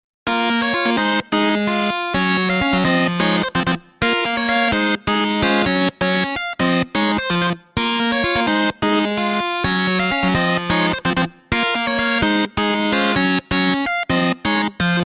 Tag: 128 bpm House Loops Harpsichord Loops 2.52 MB wav Key : Unknown